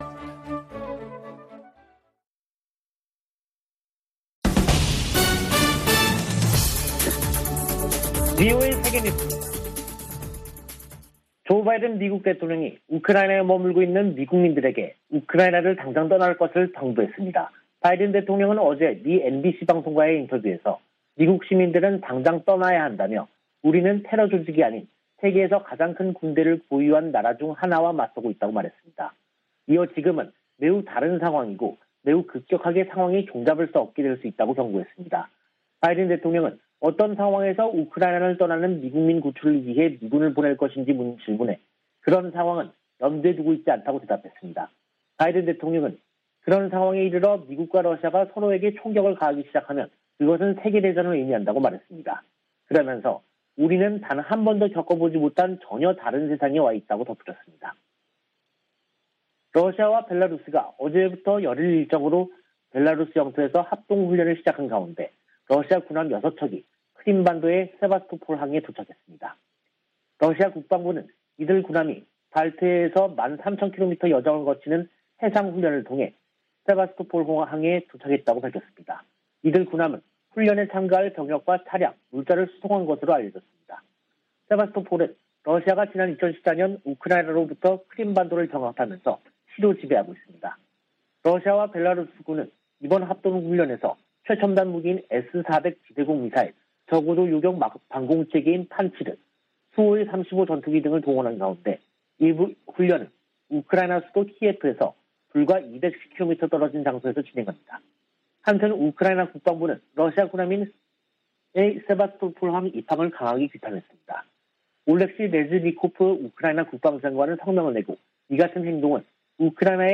VOA 한국어 간판 뉴스 프로그램 '뉴스 투데이', 2022년 2월 11일 2부 방송입니다. 토니 블링컨 미 국무부 장관은 4개국 안보협의체 '쿼드(Quad)'가 공통 도전에 대응하고 번영 기회를 찾는 것이 목표라고 밝혔습니다. 미국은 북한의 잘못된 행동을 막기 위해 유엔 회원국들이 한 목소리를 내야 한다고 밝혔습니다.